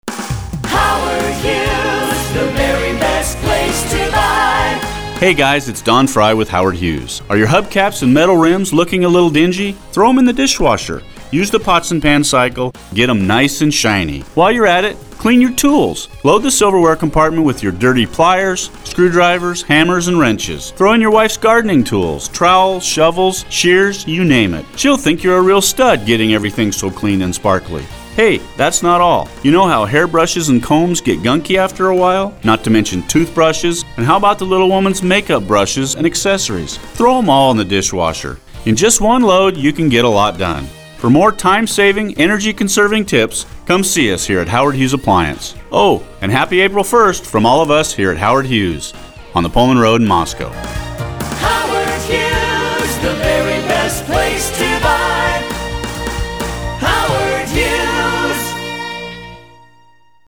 This is one of eight different commercials running heavily all day April 1st on all six commercial radio stations in the market.
Though the advertiser’s delivery and jingle sound the same as always, the copy is…a little different.